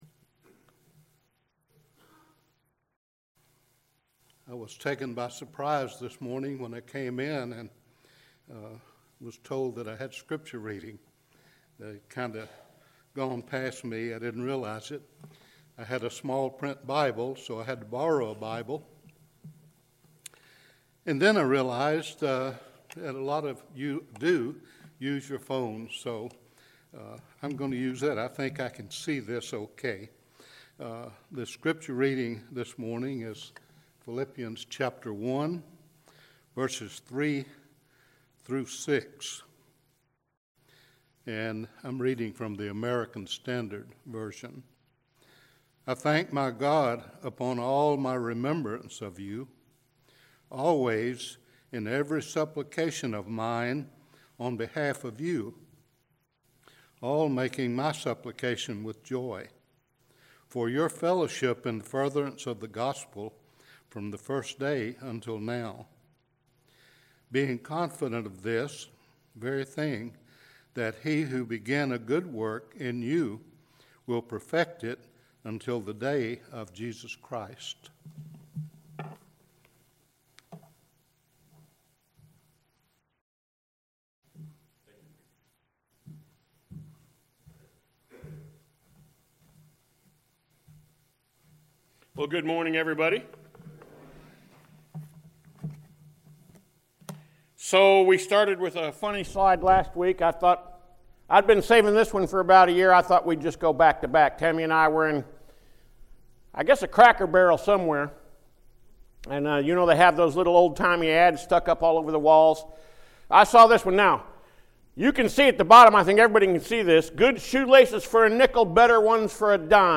Sermons | Central Church of Christ